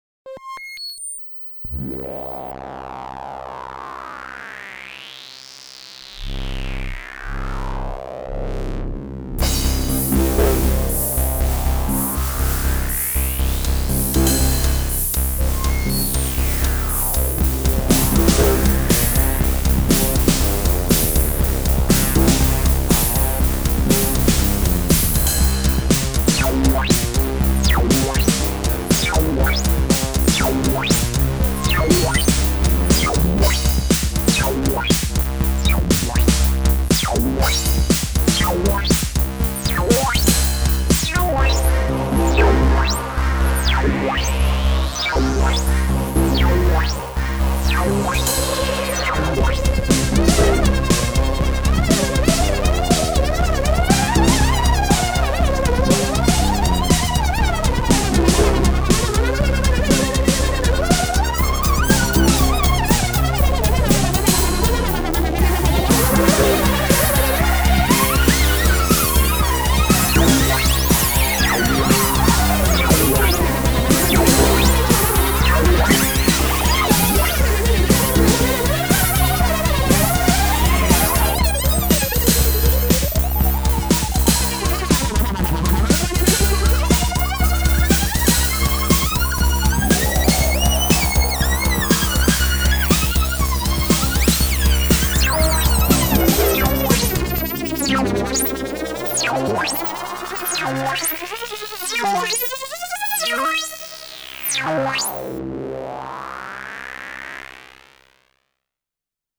editSOUND a monophonic synthesizer based on subtractive synthesis.
demo Supermix Minibrute + drum machine MFB 522